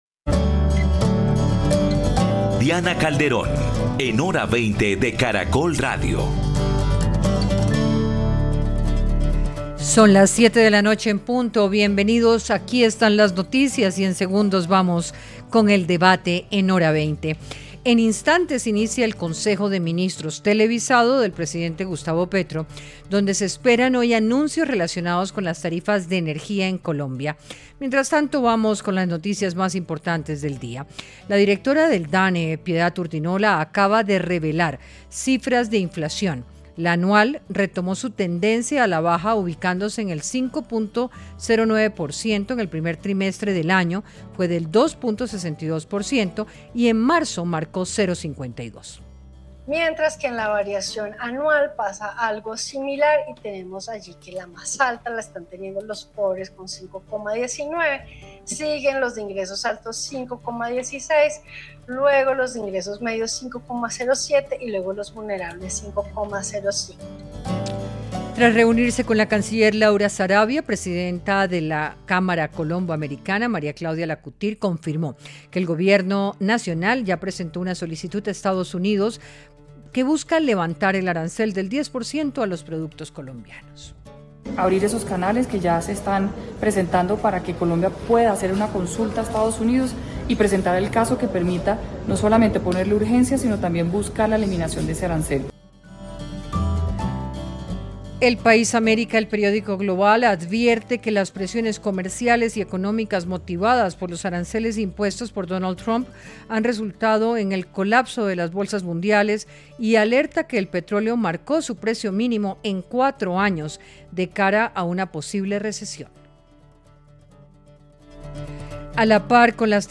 Panelistas analizaron el estado en el que se encuentra hoy la Paz Total y determinaron que ni los avances, ni los resultados esperados son favorables debido a la falta de claridades jurídicas.